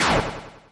snd_swing_ch1.wav